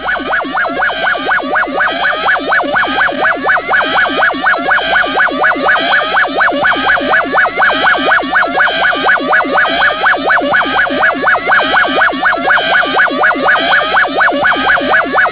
O le lapata'iga aloa'ia lenei mai le Matagaluega o le Puipuiga Lautele o le Saogalemu o le a fa'asalalauina atu i luga o so'o se leitio i taimi o fa'alavelave fa'afuase'i.
Siren.wav